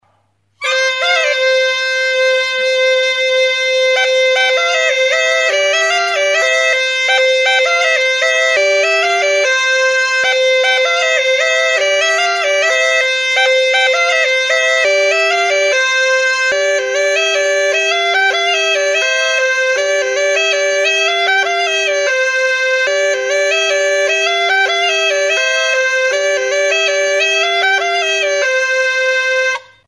Aerófonos -> Lengüetas -> Simple (clarinete)
Aerófonos -> Lengüetas -> Cornamusa
Grabado con este instrumento.
Xirola klarinete bikoitza duen xirolarrua da. 2 tutu ditu; bat melodikoa, 6 zulorekin tonu aldaketarako, eta bestea nota pedala ematen duena.
Do tonuan dago.